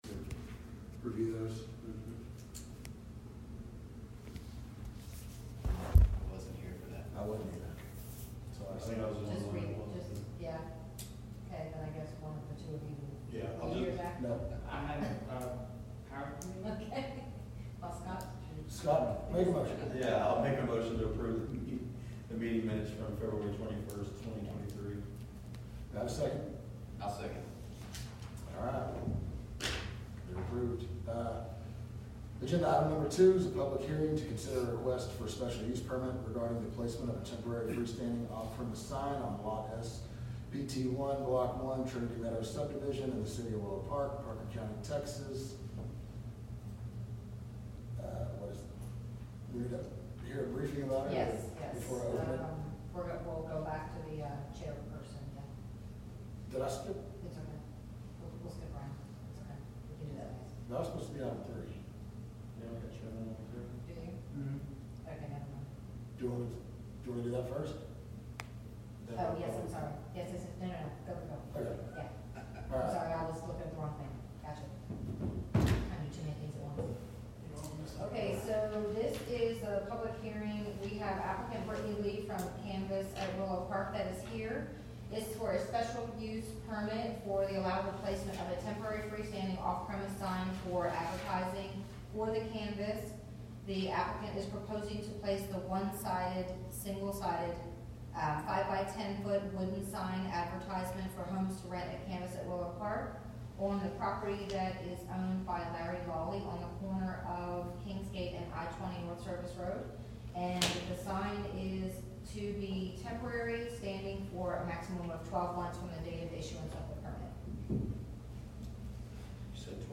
Location and Time: El Chico City Hall, 120 El Chico, Suite A, Willow Park, Texas 76087, 6PM